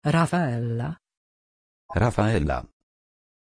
Pronunția numelui Raphaëlla
pronunciation-raphaëlla-pl.mp3